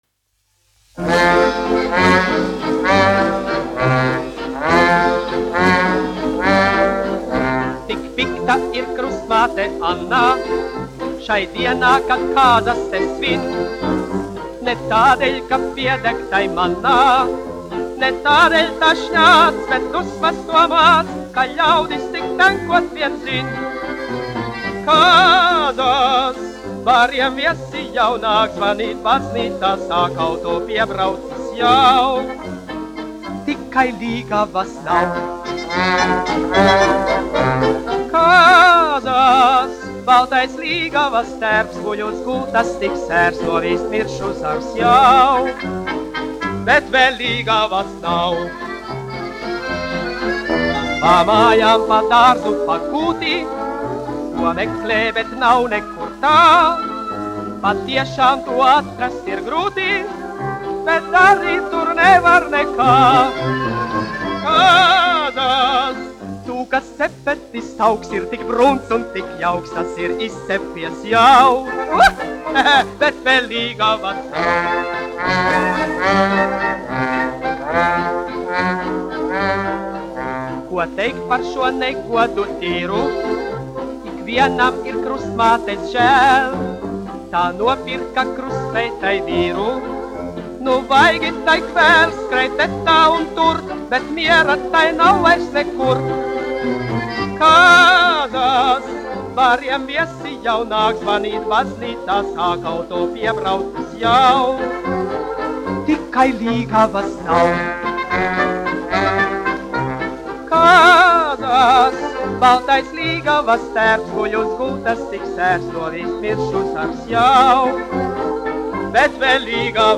1 skpl. : analogs, 78 apgr/min, mono ; 25 cm
Populārā mūzika